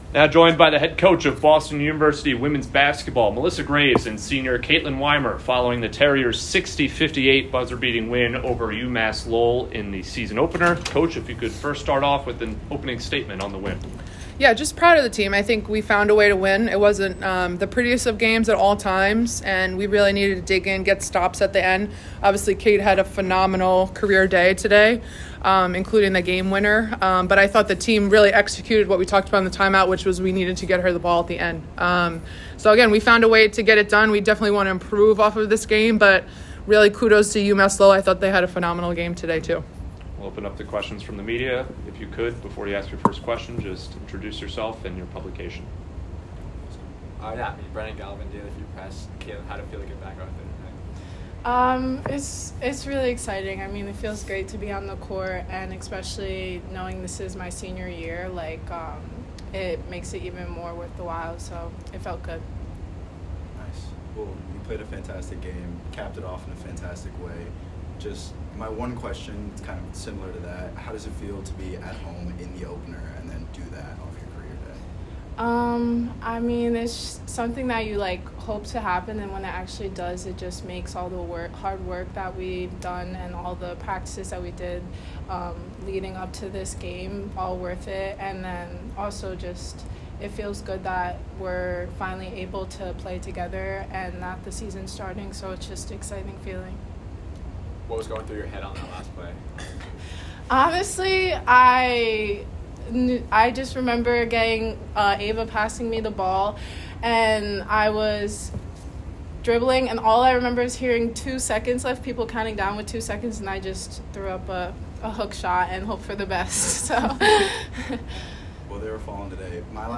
WBB_UML_Postgame.mp3